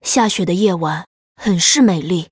文件 文件历史 文件用途 全域文件用途 Yoshua_tk_01.ogg （Ogg Vorbis声音文件，长度2.3秒，95 kbps，文件大小：27 KB） 文件说明 源地址:游戏语音解包 文件历史 点击某个日期/时间查看对应时刻的文件。 日期/时间 缩略图 大小 用户 备注 当前 2019年1月20日 (日) 04:28 2.3秒 （27 KB） 地下城与勇士  （ 留言 | 贡献 ） 分类:寒冰之休亚 分类:地下城与勇士 源地址:游戏语音解包 您不可以覆盖此文件。